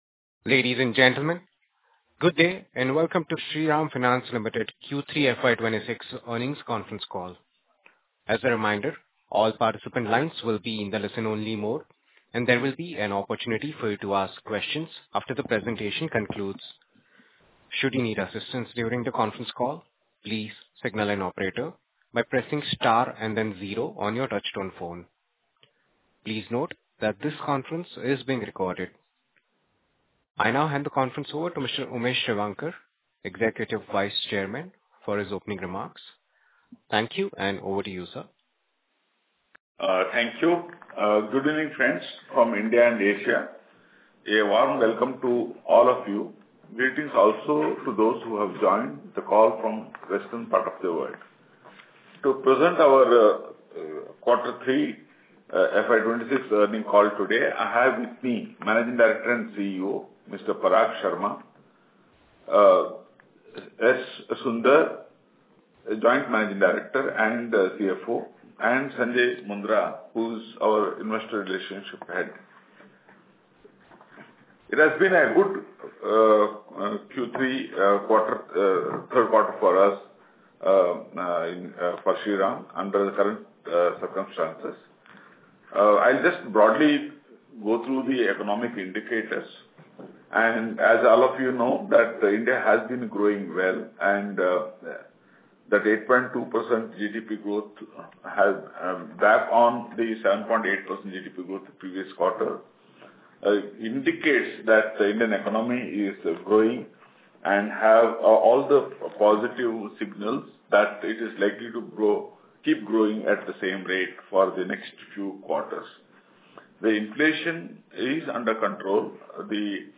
Earnings Call Audio Available